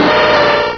pokeemerald / sound / direct_sound_samples / cries / machamp.aif